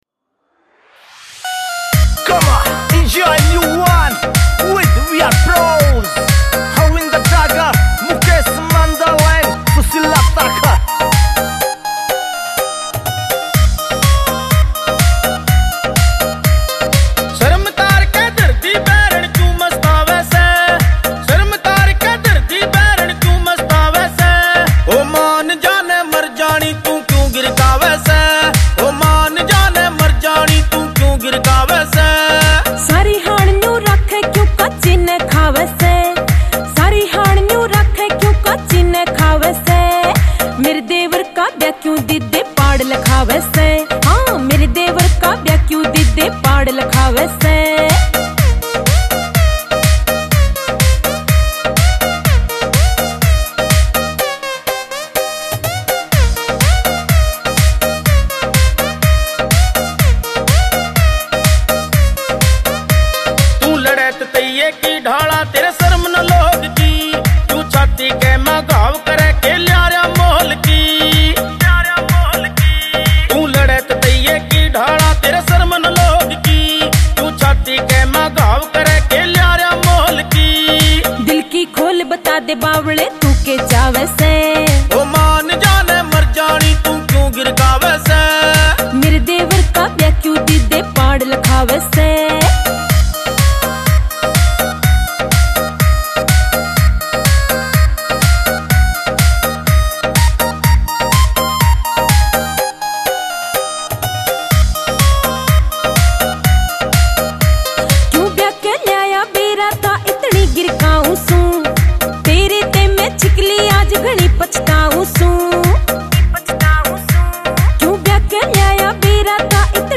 Love Songs